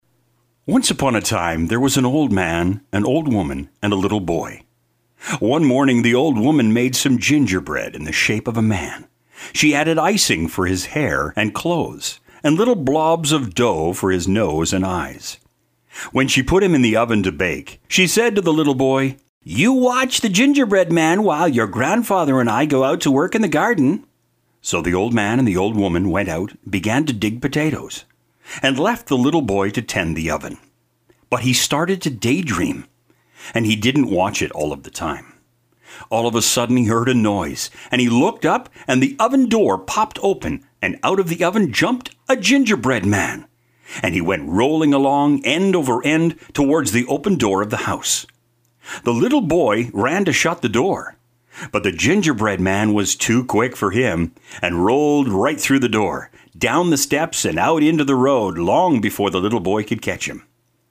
Mature Canadian Male Voice. Warm and engaging,or punchy and hard sell, adaptable for all situations.
Childs Book Narration